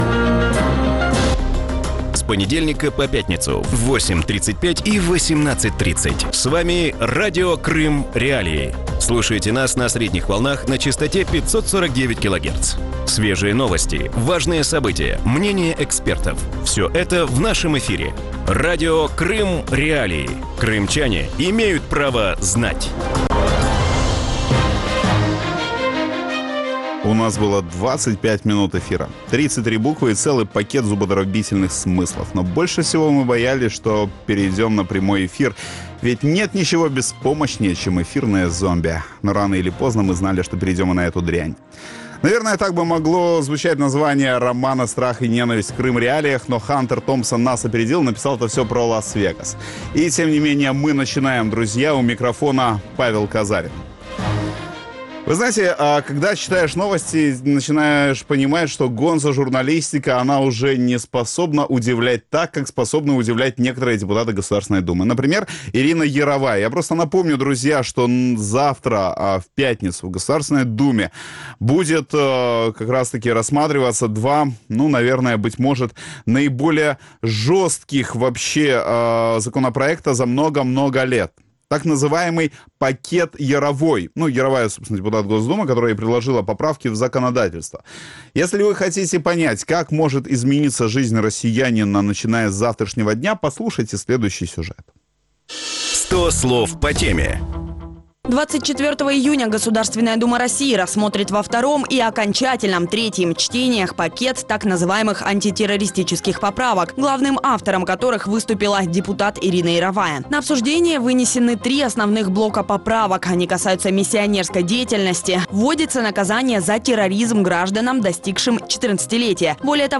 В вечернем эфире Радио Крым.Реалии обсуждают «антитеррористические» поправки в федеральные законы, внесенные депутатом Госдумы Ириной Яровой и сенатором Виктором Озеровым. К чему приведет ужесточение российского законодательства, зачем кардинально изменяют законы в последние дни каденции Госдумы и какие нововведения затронут непосредственно крымчан?